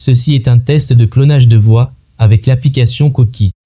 Cloner sa voix avec l’IA
Extrait Généré sur Coquie TTS
Mon préféré a longtemps été Coquie TTS dont est tiré l’exemple audio ici.
Test-coquie-tts.mp3